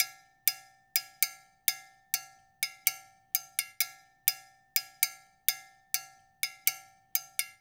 LAY COWBELL.wav